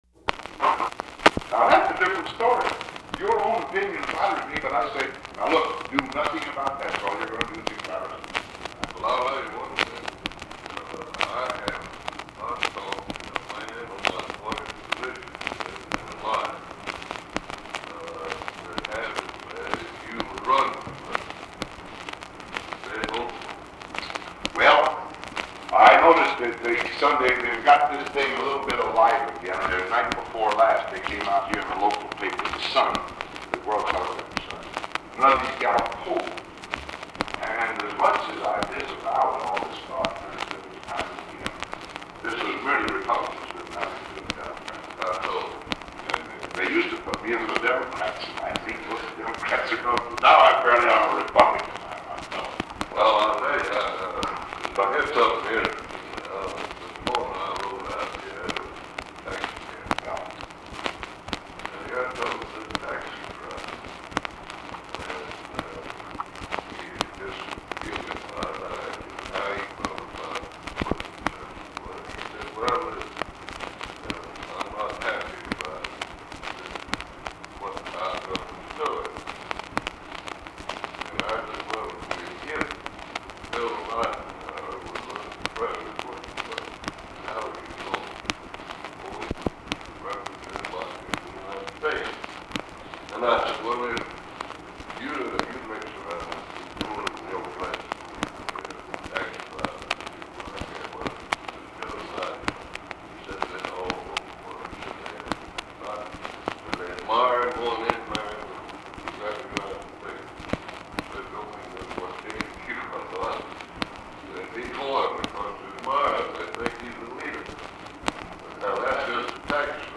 Much of the recording is difficult to understand.
Secret White House Tapes | Dwight D. Eisenhower Presidency